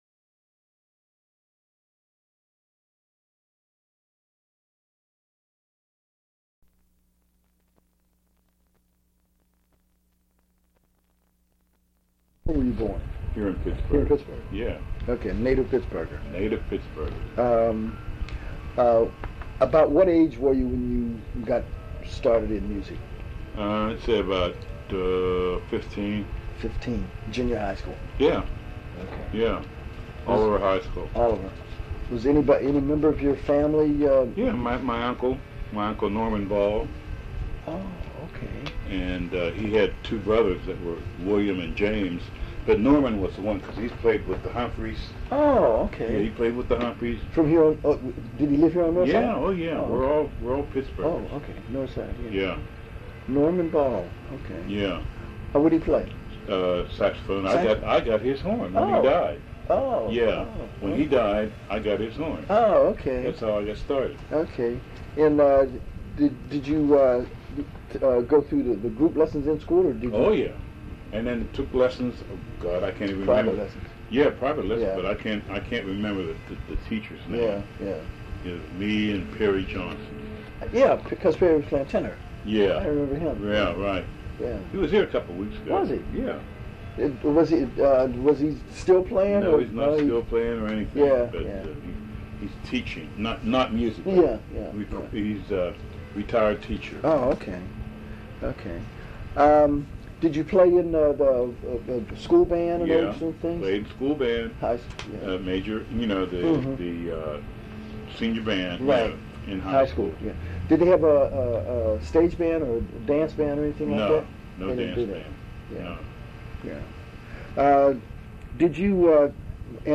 Beginning music